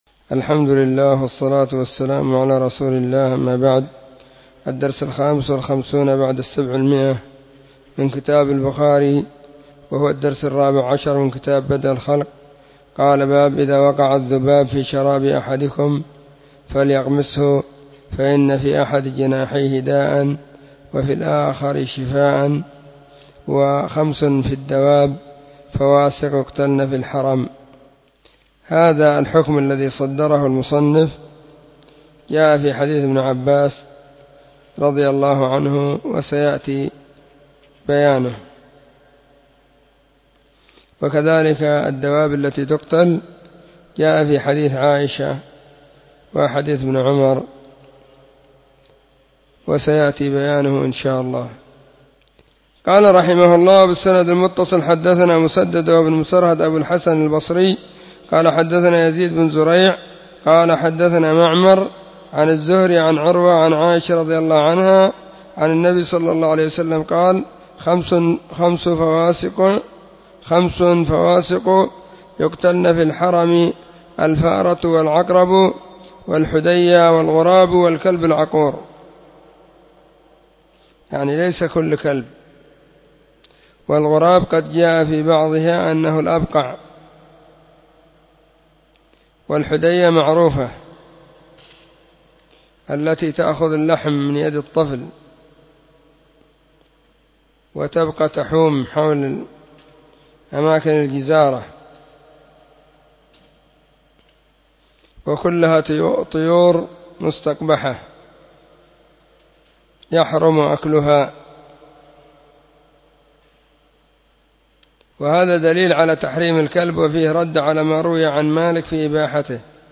🕐 [بين مغرب وعشاء – الدرس الثاني] 📢 مسجد الصحابة – بالغيضة – المهرة، اليمن حرسها الله.
🕐 [بين مغرب وعشاء – الدرس الثاني]